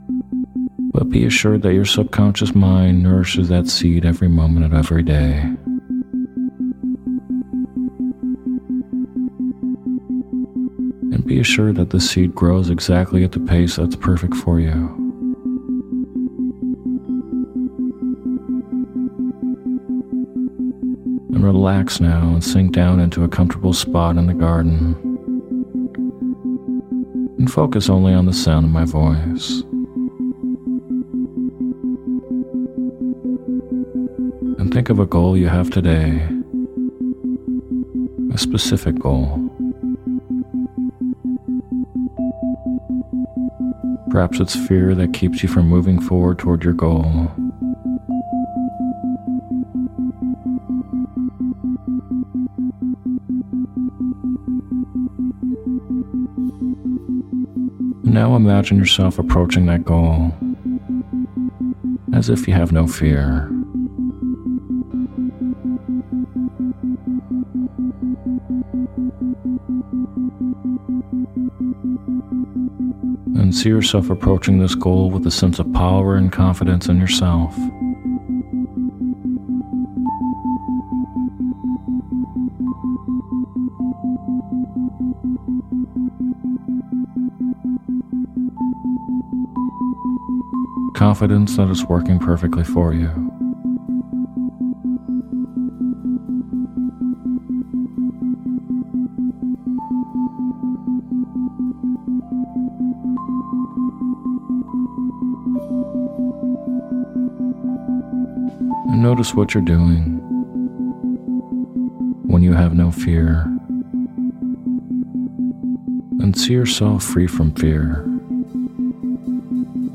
Sleep Hypnosis For Planting Seeds With Isochronic Tones